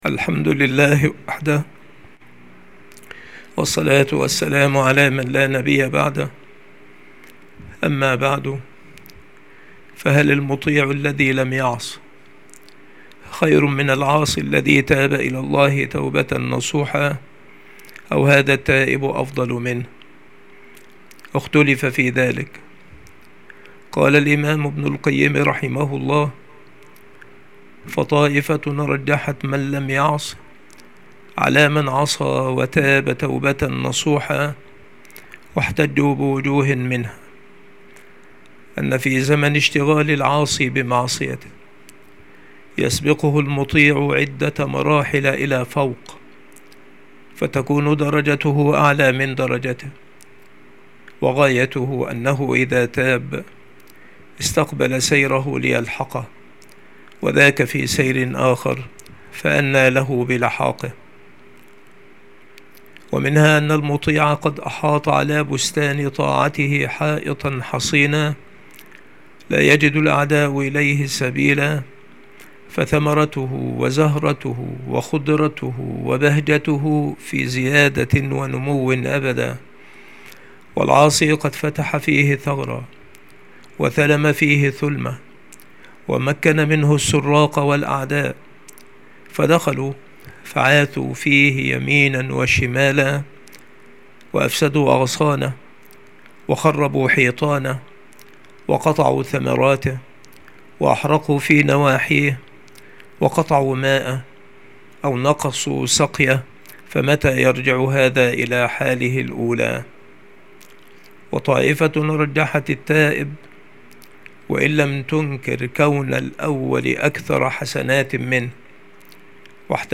• مكان إلقاء هذه المحاضرة : المكتبة - سبك الأحد - أشمون - محافظة المنوفية - مصر